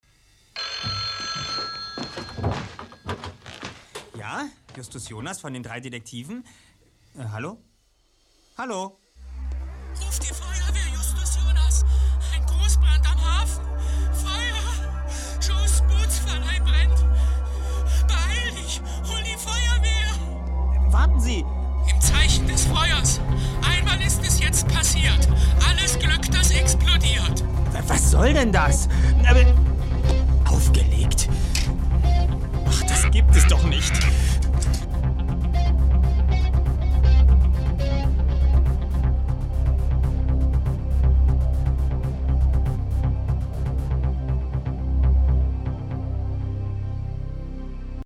Habe hier mal ein Beispiel einer kurzen Hörspielszene vermusikalisiert.